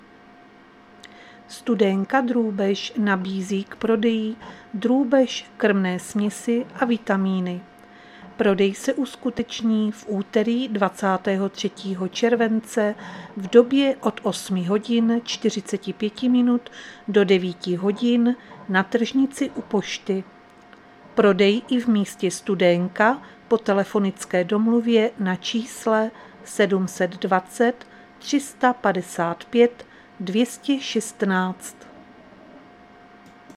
Záznam hlášení místního rozhlasu 22.7.2024